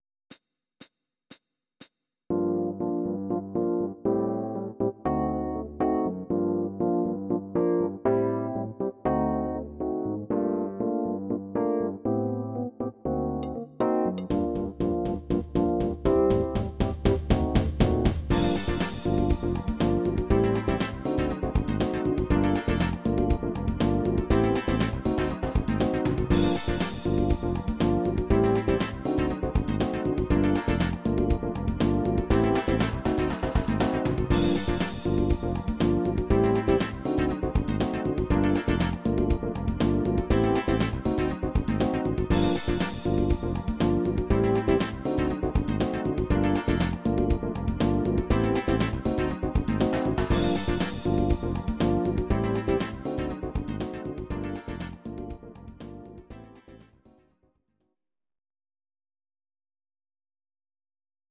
Audio Recordings based on Midi-files
Disco, Jazz/Big Band, Instrumental, 1970s